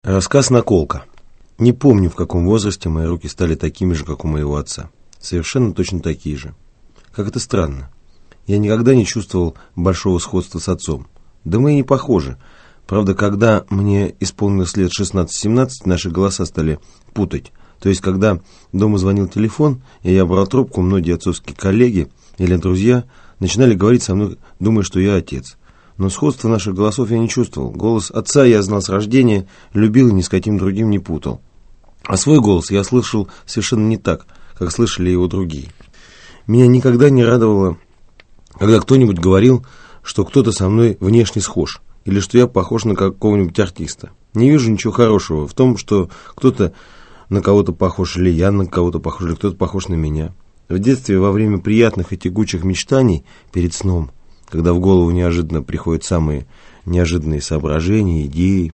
Aудиокнига Следы на мне Автор Евгений Гришковец Читает аудиокнигу Евгений Гришковец.